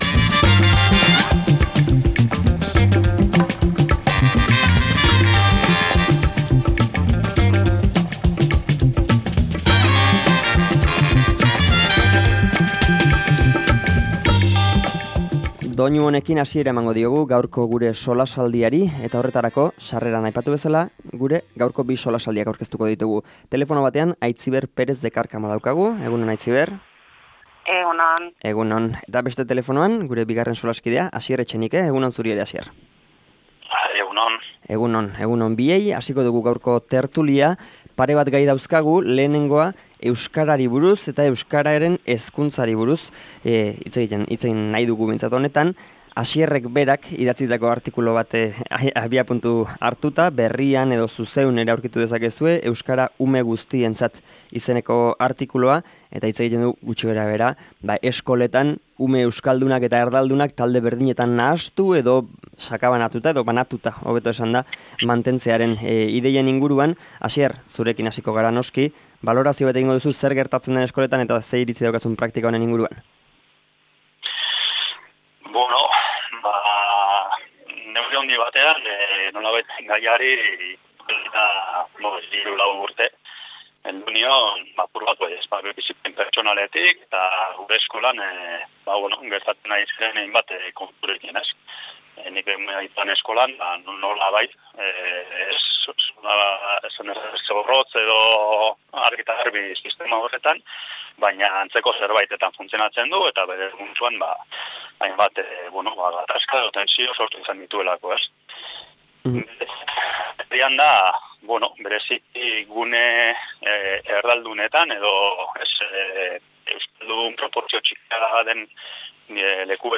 Solasaldia | Eskolan ume euskaldunak erdaldunekin nahasteari buruz, eta abortuaren kontzientzia-objekzioaz | Hala Bedi